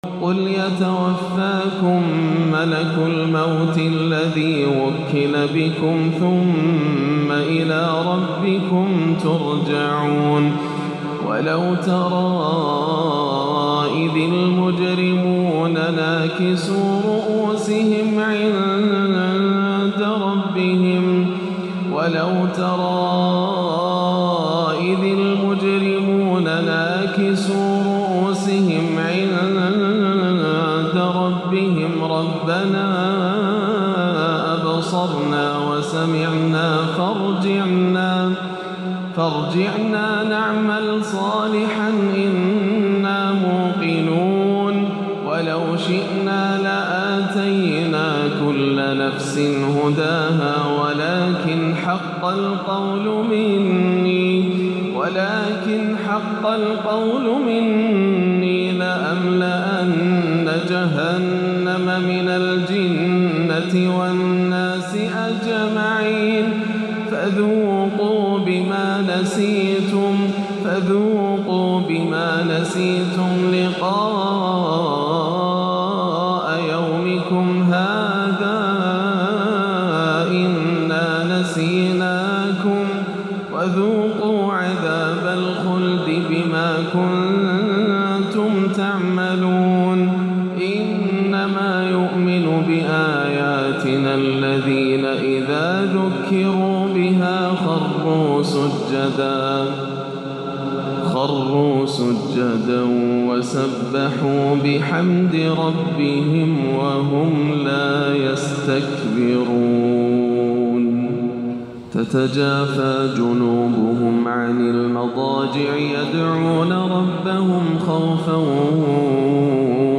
أجمل فجريات شيخنا الغالي منذ سنوات طويلة لسورتي السجدة والإنسان - الجمعة 7-12 > عام 1437 > الفروض - تلاوات ياسر الدوسري